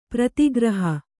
♪ prati graha